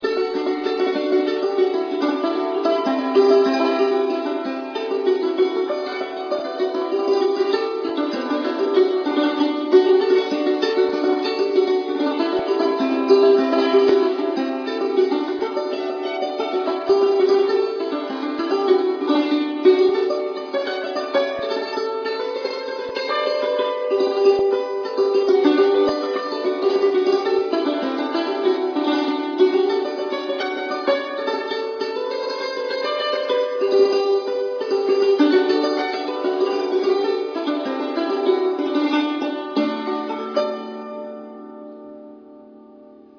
reel
hammered dulcimer